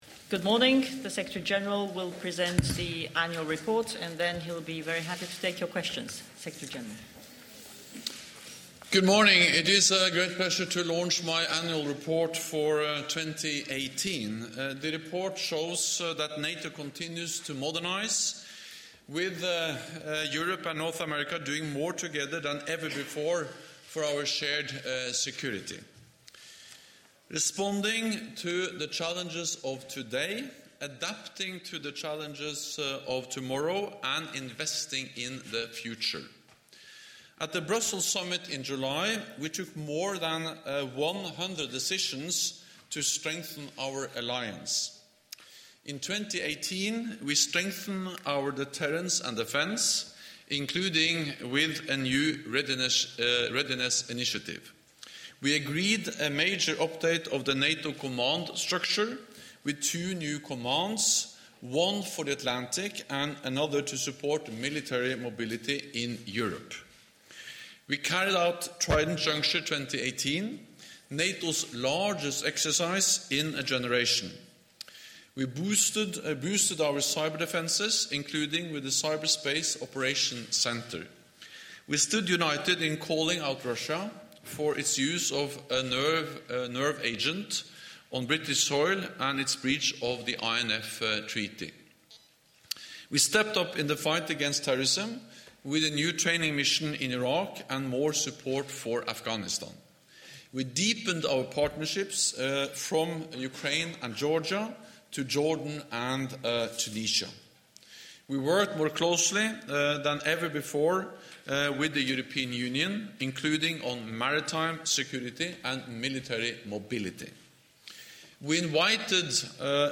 Press conference
by the NATO Secretary General Jens Stoltenberg at the launch of his Annual Report for 2018